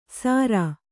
♪ sārā